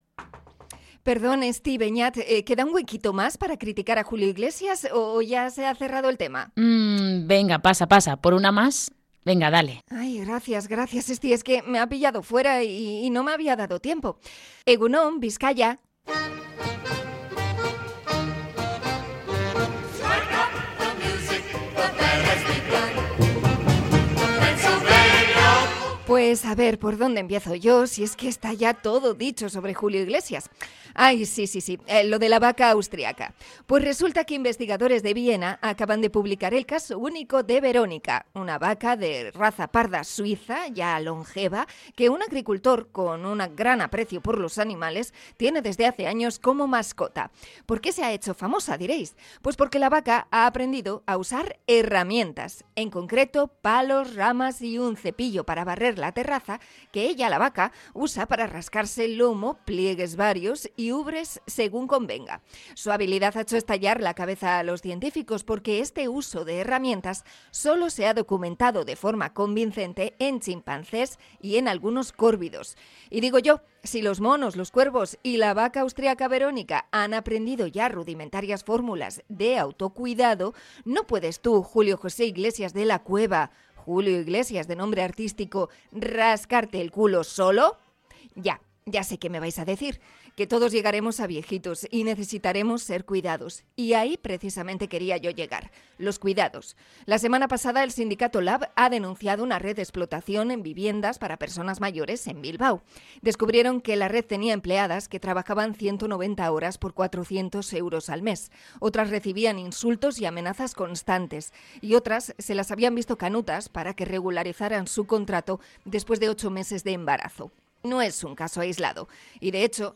Comentario sobre la indefensión de las trabajadoras del hogar